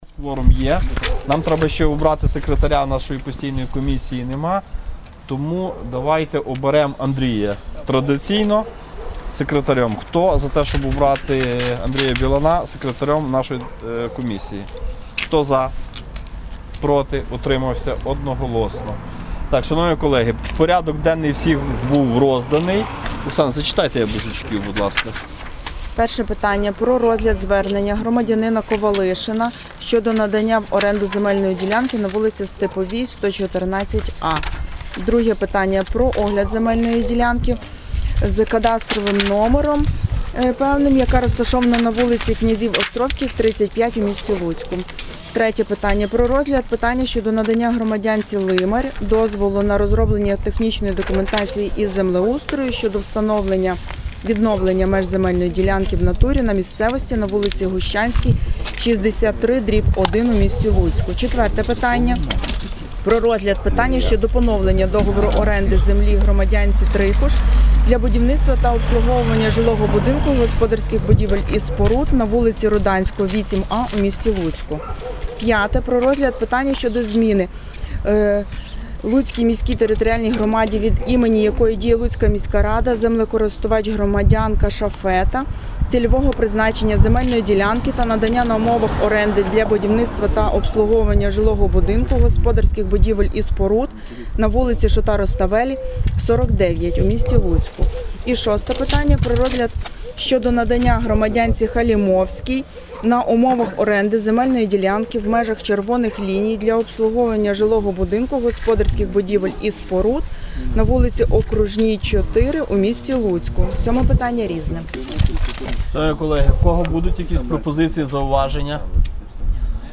Постійна комісія 11.05.2023 Виїздне засідання